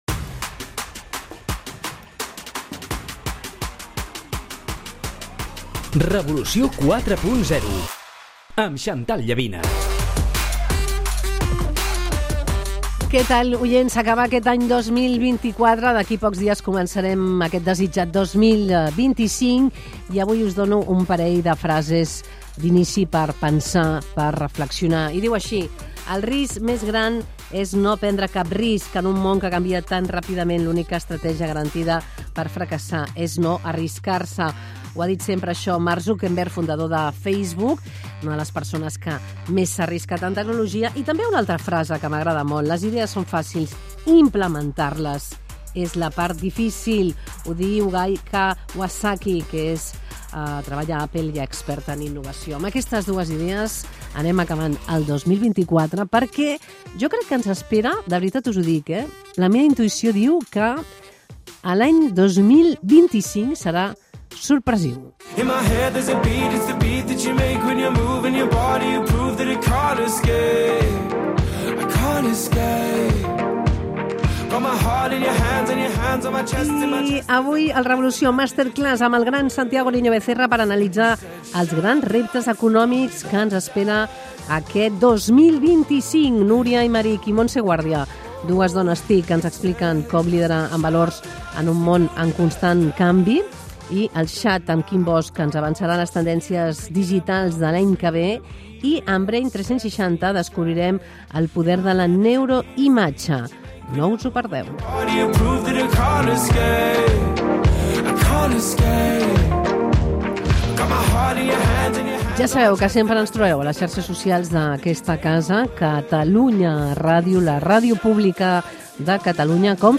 L'economista Santiago Nio-Becerra ens fa una classe magistral sobre el futur financer de Catalunya, l'estat espanyol i el mn.